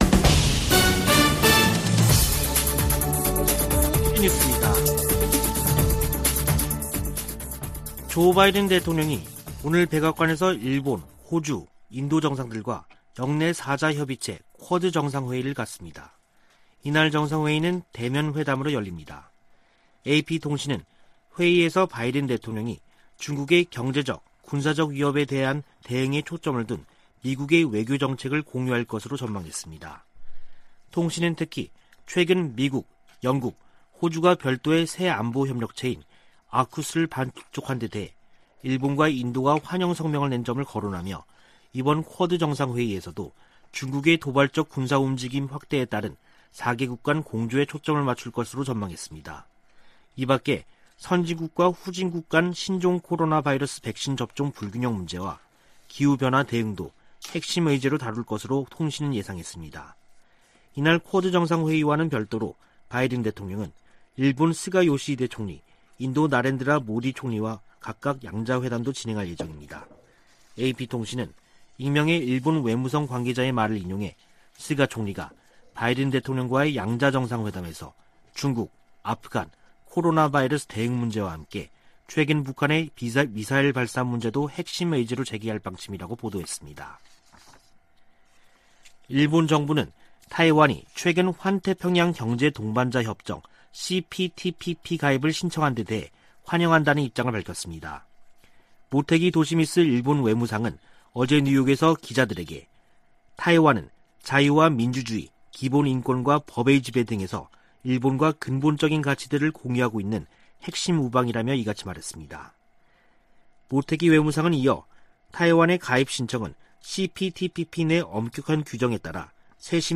VOA 한국어 간판 뉴스 프로그램 '뉴스 투데이', 2021년 9월 24일 3부 방송입니다. 미국은 북한 식량난과 관련해 주민들의 안위를 우려하고 있다고 미 고위 당국자가 밝혔습니다. 유엔이 북한을 또다시 식량부족국으로 지정하며 코로나 여파 등으로 식량안보가 더 악화했다고 설명했습니다. 한국 전쟁 종전선언은 북한과의 신뢰 구축 목적이 크지만 미국과 한국이 원하는 결과로 이어지기는 어렵다고 미국의 전문가들이 분석했습니다.